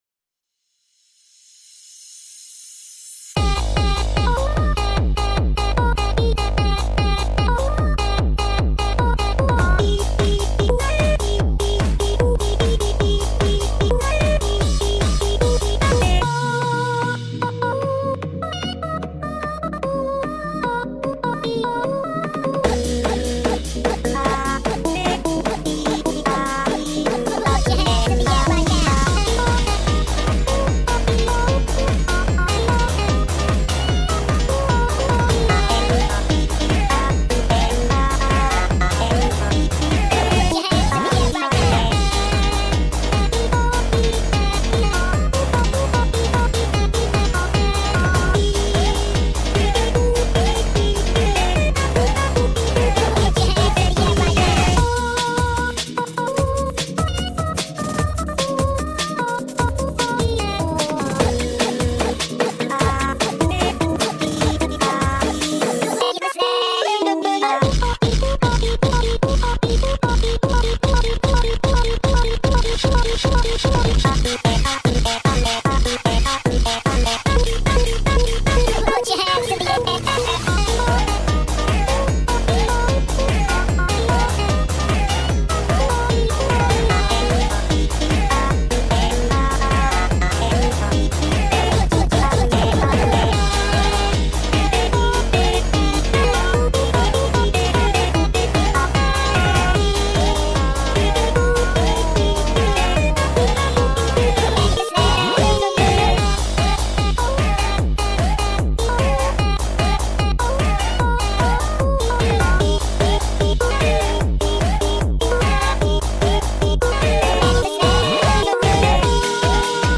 slowed _ daycore